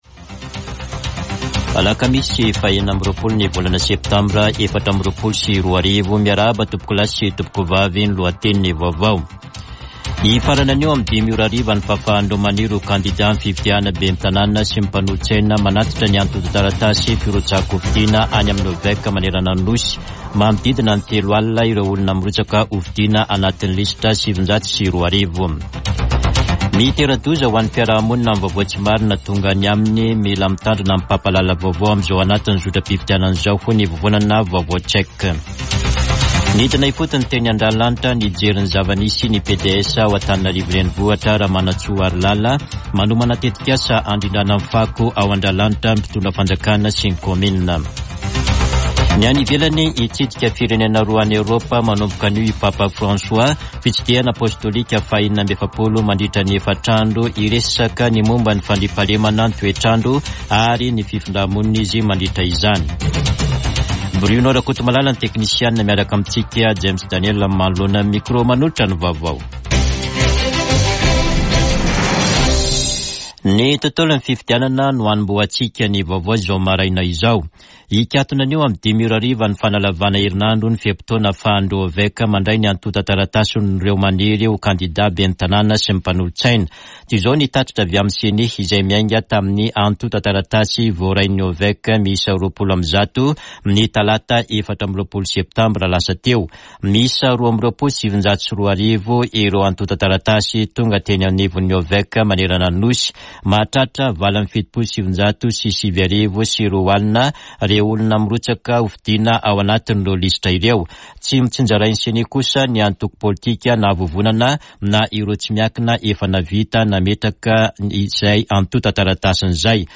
[Vaovao maraina] Alakamisy 26 septambra 2024